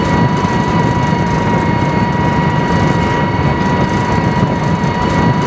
crawler_idle.wav